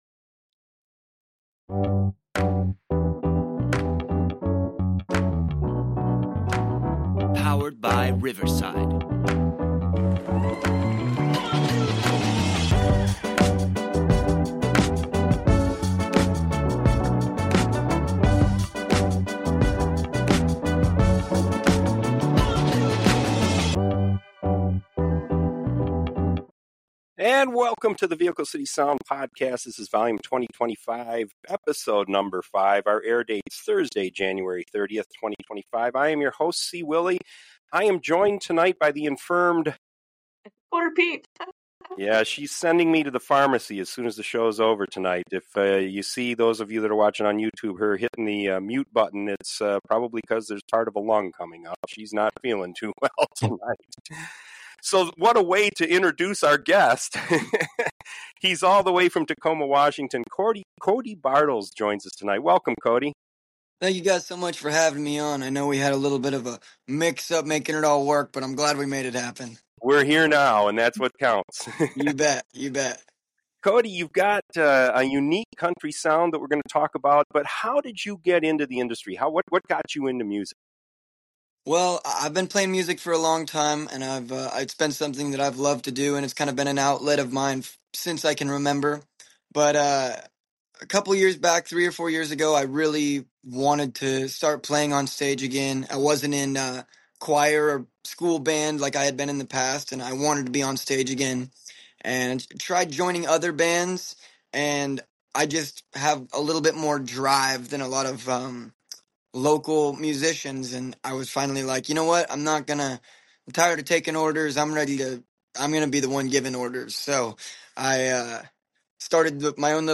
classic country